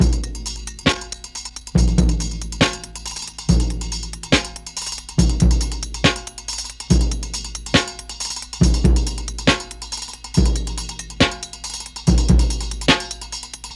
NEW MAGIC WAND Drum Break (139.54bpm).wav